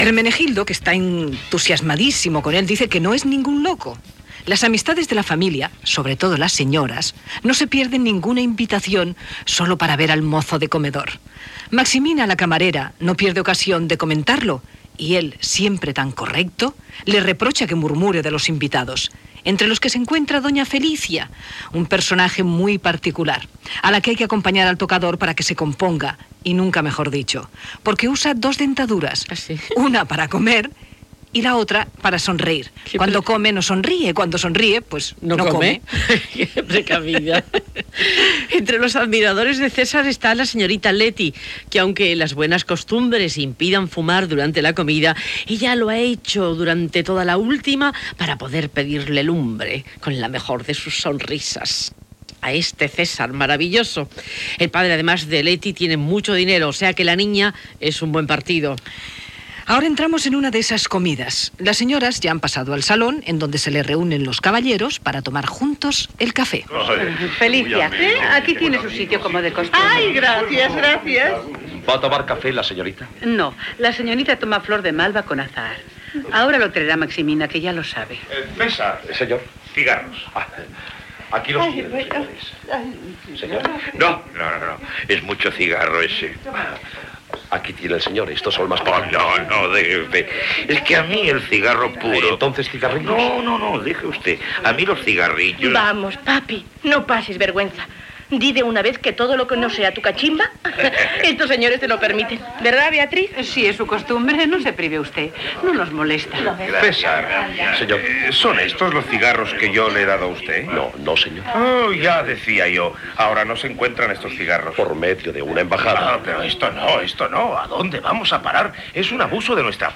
Argument de l'obra i fragment de radio teatre del "Teatro invisible" de Radio Nacional de España.
Ficció